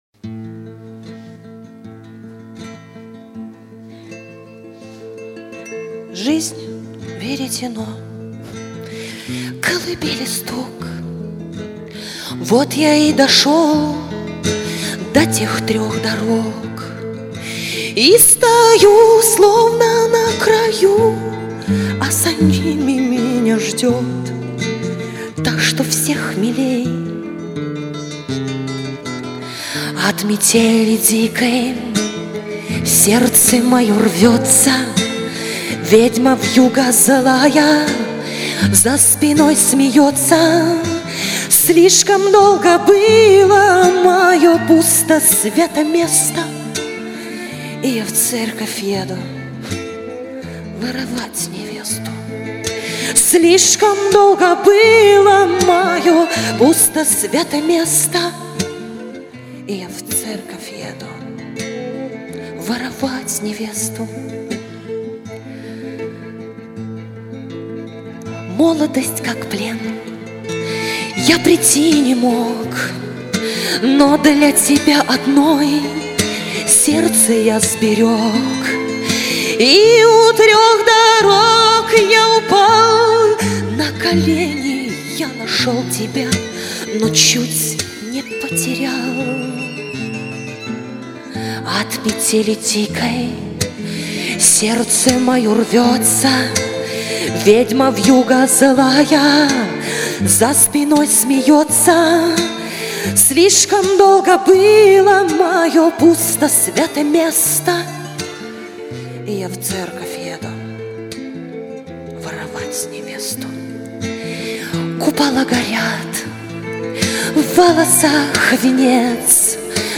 Genre: Шансон